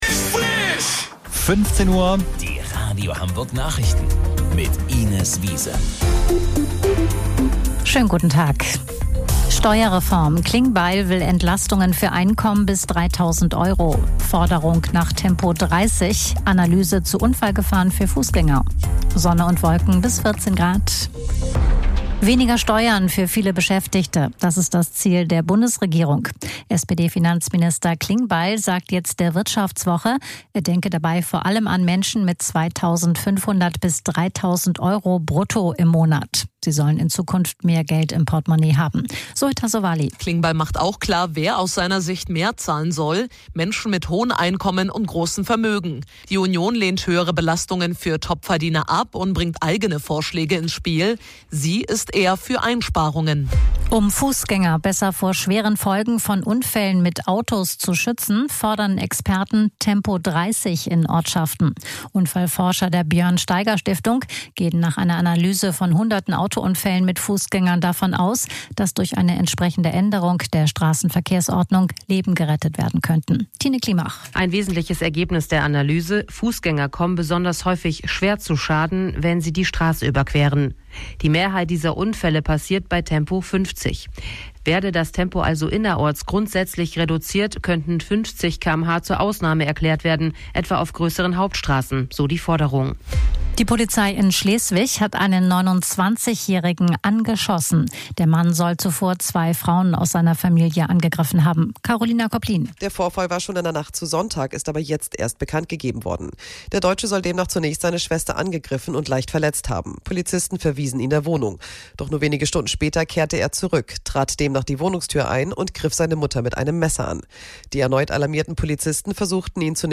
Radio Hamburg Nachrichten vom 23.04.2026 um 15 Uhr